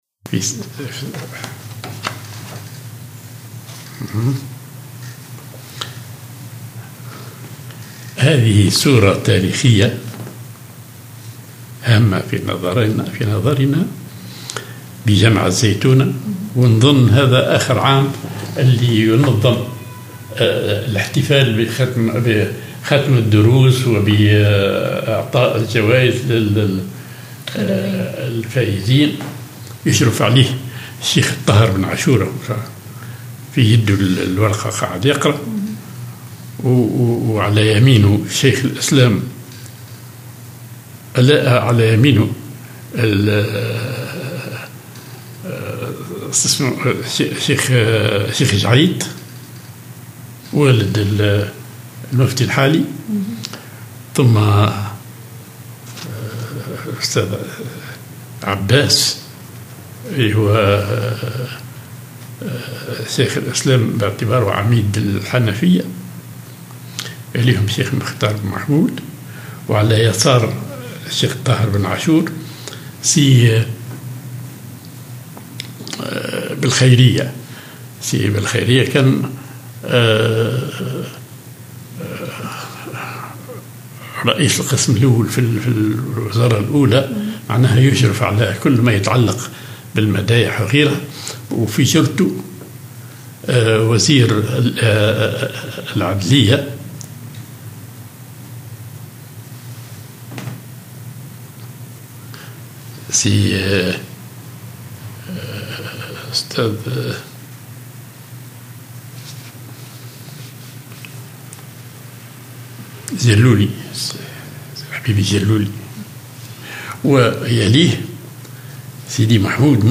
بجامع الزيتونة احتفال ختم الدروس